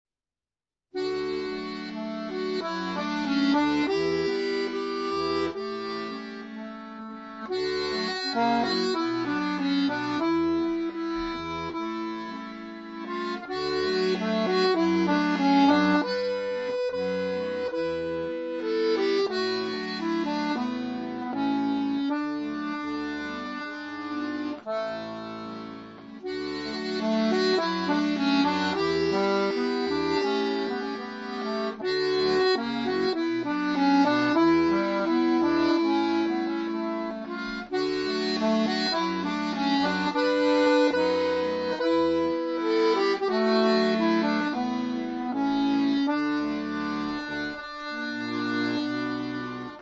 Notes from the Arranger: An elegant romantic melody.